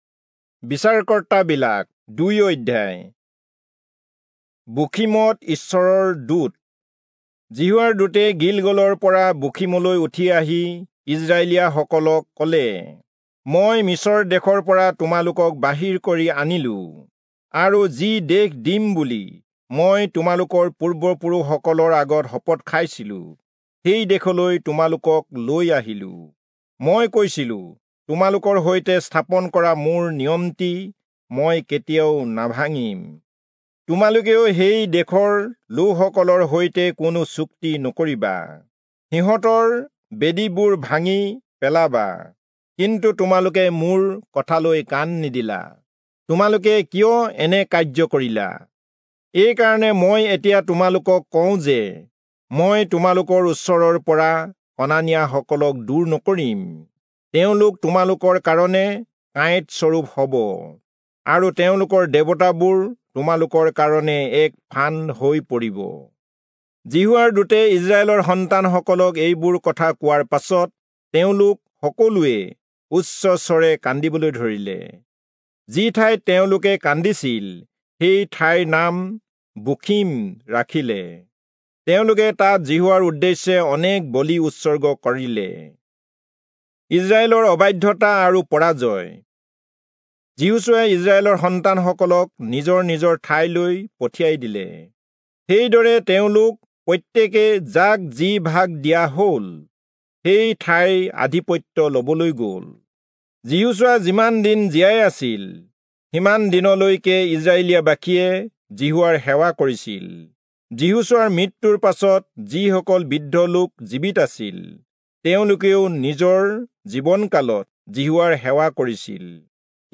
Assamese Audio Bible - Judges 4 in Lxxrp bible version